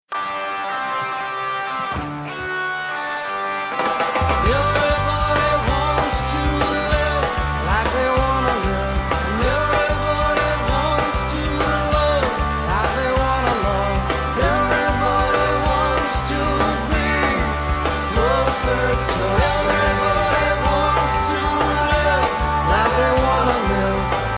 un petit générique!